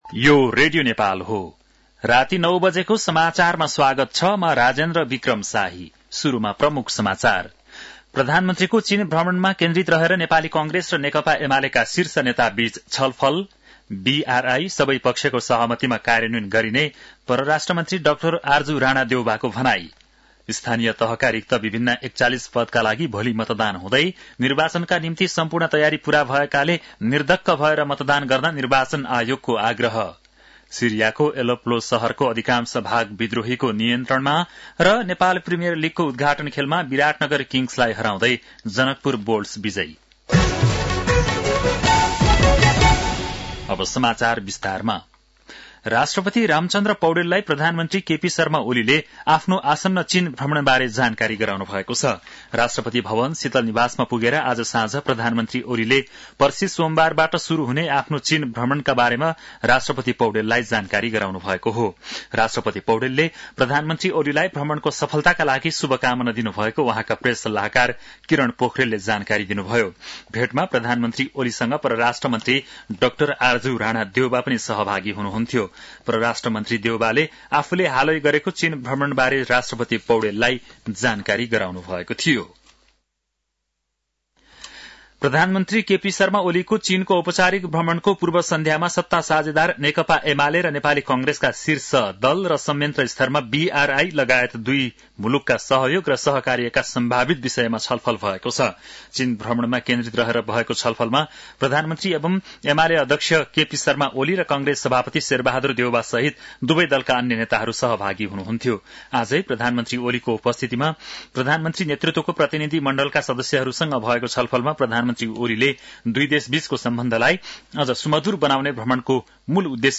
An online outlet of Nepal's national radio broadcaster
बेलुकी ९ बजेको नेपाली समाचार : १६ मंसिर , २०८१